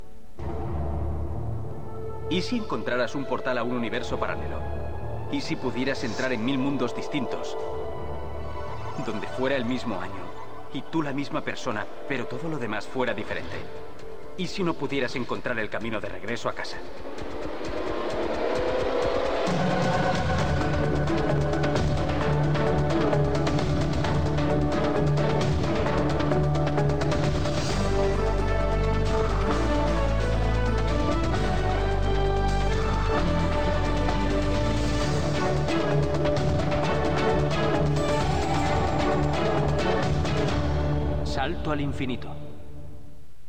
Der Text der fünften Staffel gleicht jenem der vierten Staffel. Offenbar wurde hier sogar der identische Ton über das Intro gelegt.
sliders_intro_s5_spanien.mp3